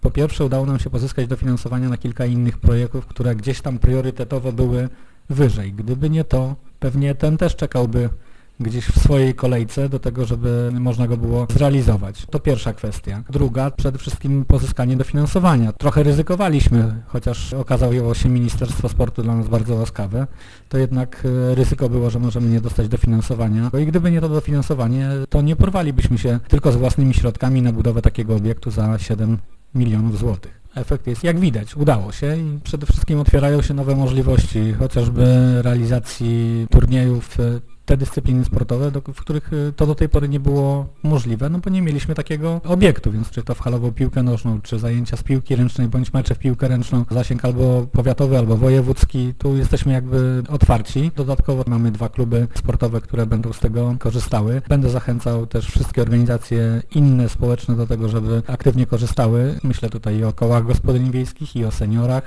Uroczyste otwarcie nowego obiektu odbyło się we wtorek 1 września przy okazji inauguracji nowego roku szkolnego.
Wójt Marcin Gąsiorowski przyznaje, że na realizację tej inwestycji złożyło się kilka czynników: